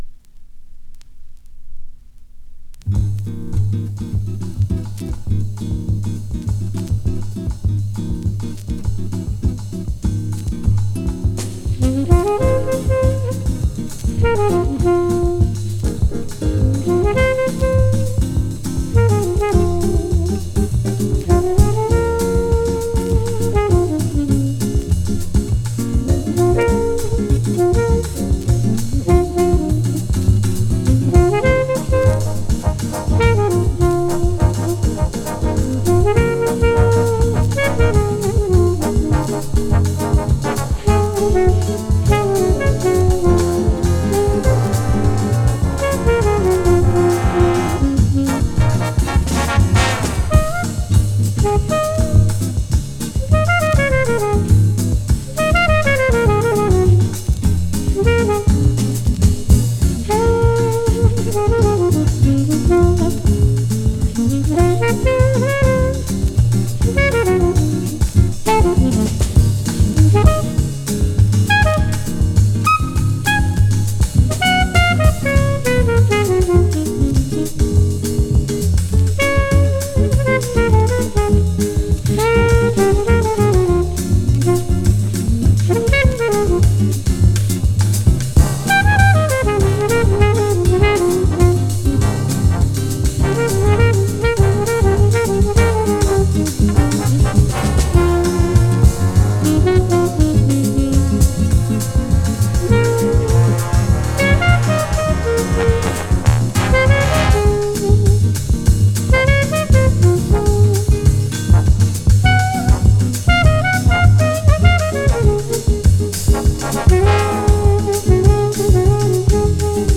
Trombone
Trumpet/Flugelhorn
French Horn
Guitar